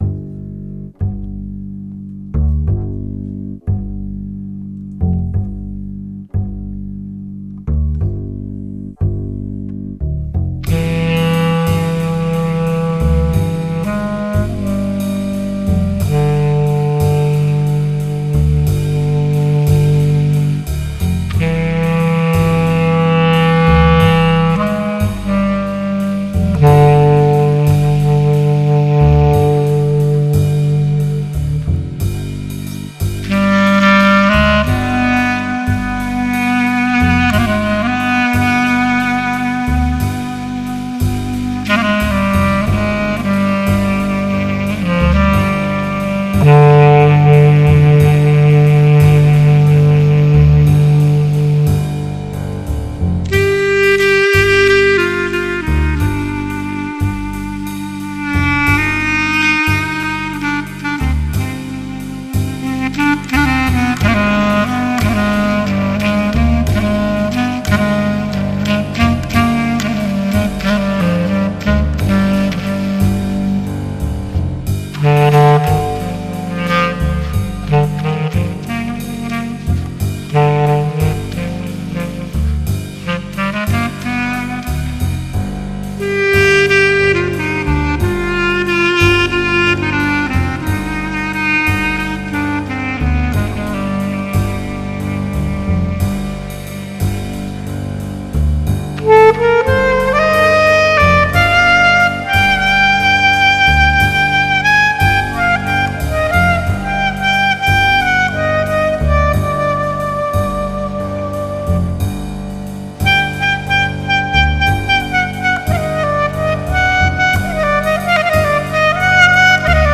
Eine kleine Klarinettenimprovisation